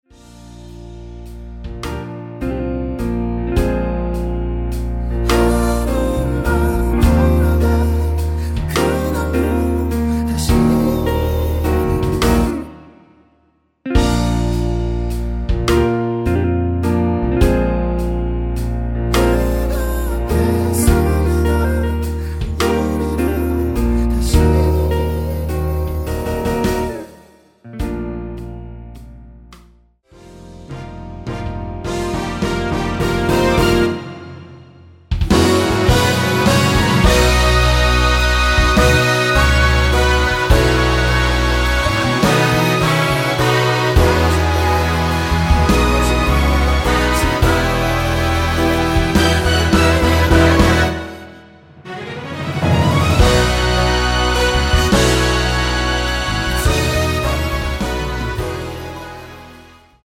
원키 코러스 포함된 MR 입니다.(미리듣기 참조)
Bb
앞부분30초, 뒷부분30초씩 편집해서 올려 드리고 있습니다.